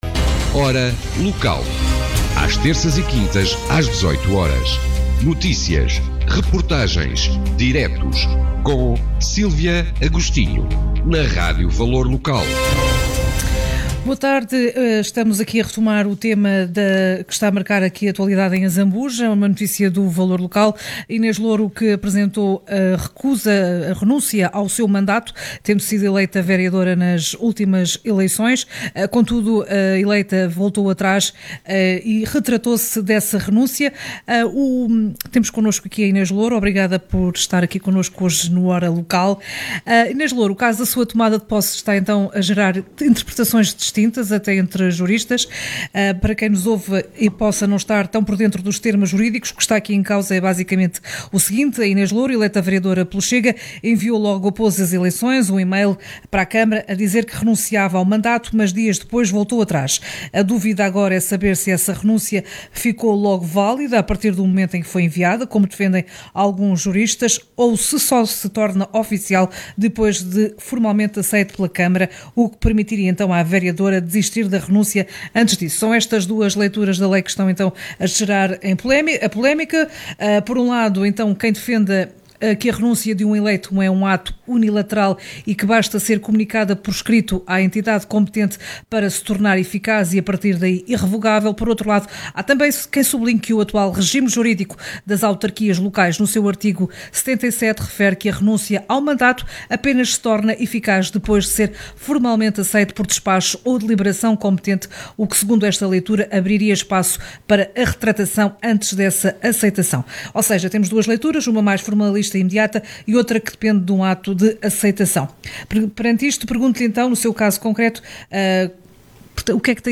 Hora Local- Entrevista Inês Louro sobre a polémica da renúncia de mandato - Jornal e Rádio Valor Local Regional - Grande Lisboa, Ribatejo e Oeste